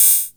Index of /musicradar/essential-drumkit-samples/Vintage Drumbox Kit
Vintage Open Hat 03.wav